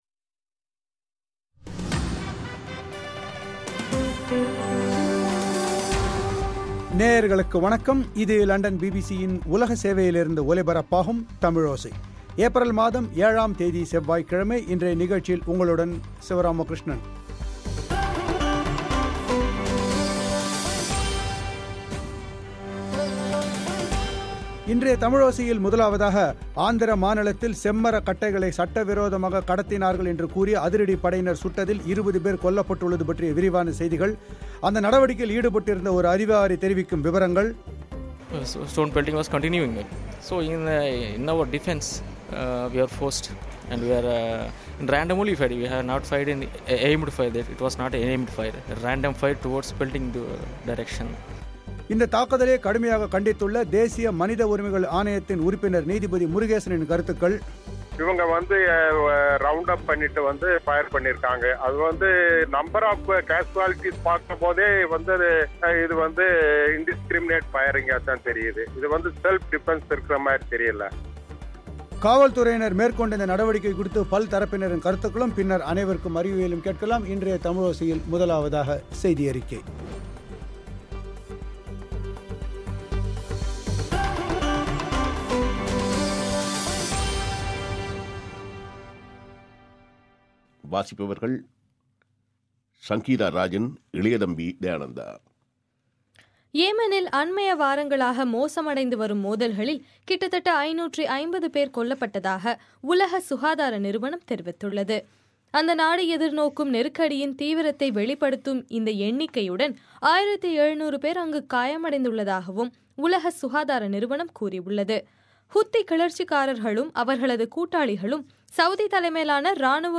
அதில் ஈடுபட்டிருந்த ஒரு அதிகாரி தெரிவிக்கும் விபரங்கள்